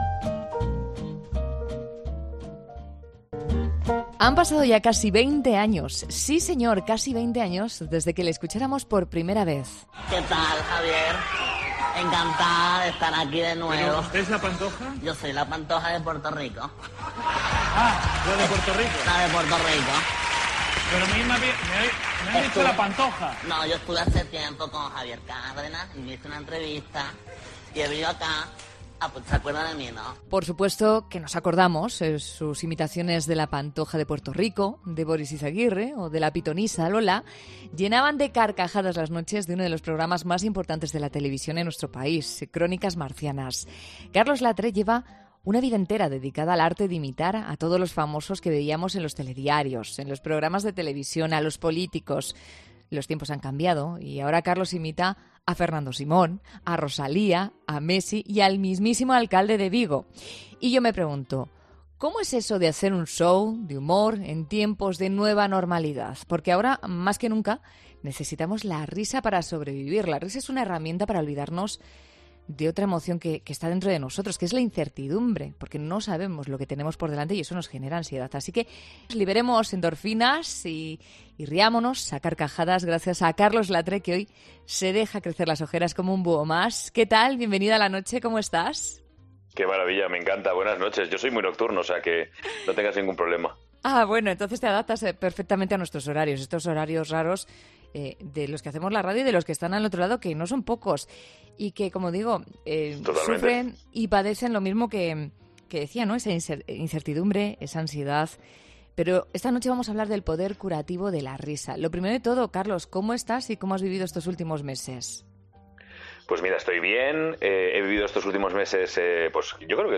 Carlos Latre ha estado en 'La Noche de COPE' para explicar algunos detalles de su última gira